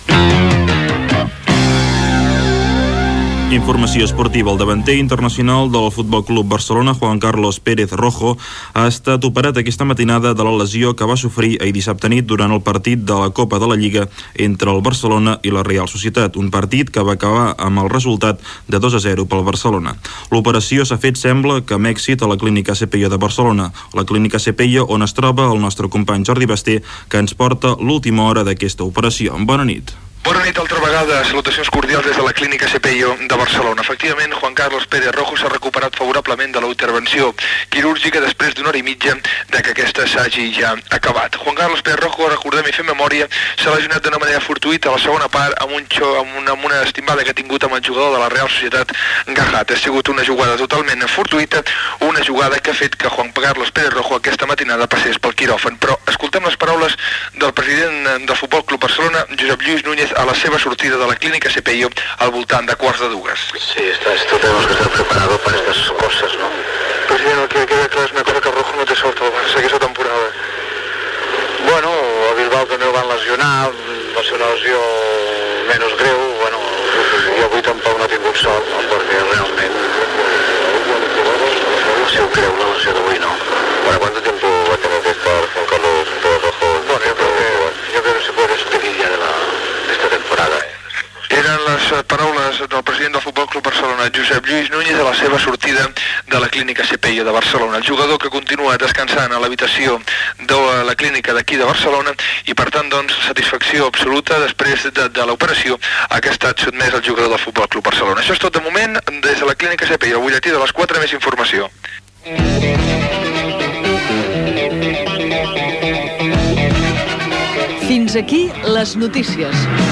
Crònica esportiva de Jordi Basté sobre l'operació del futbolista Juan Carlos Pérez Rojo a la clínica Asepeyo, amb declaracions del president del F.C: Barcelona Josep Lluís Núñez.
Esportiu
FM